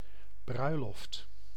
Ääntäminen
IPA : /ˈwɛdɪŋ/ IPA : [ˈwɛɾɪŋ]